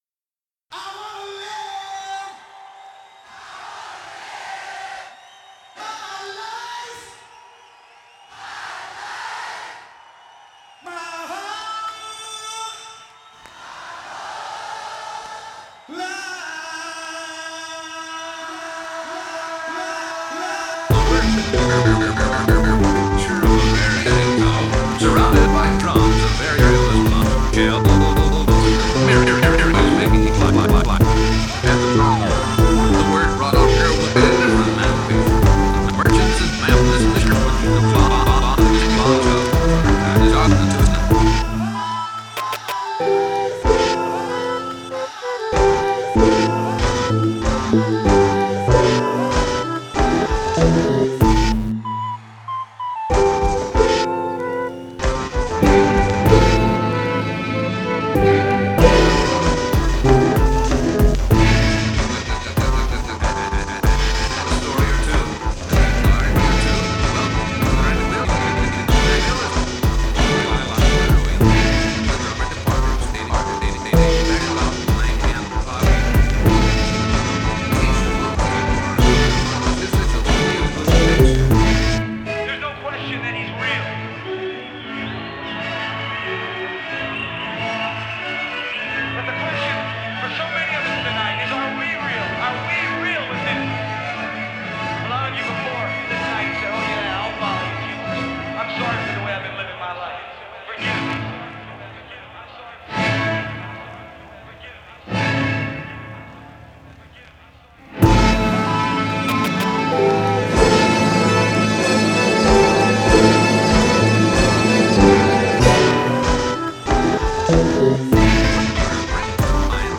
[Instrumental Hip-Hop... I think] Unfocused (192 kbps mp3)
I went through and recorded some of our more obscure vinyls while bored, chopped em up a little, and made this. 100% created in modplug. This is the first time I've tried making a track almost entirely of samples from records.
Forgot some vinyl ticks, but never mind.
I would call this a synced collage.
But it sounds (to me) like you forgot to put a beat underneath it to keep it going.
I meant it could use some drums.
It definitely has a beat, a cyclic movement, that's why I called it 'synced'.